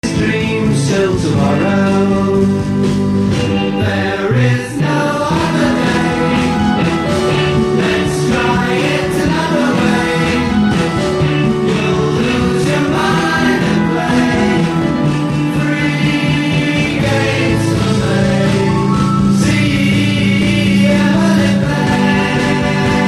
Full-on psychedelic.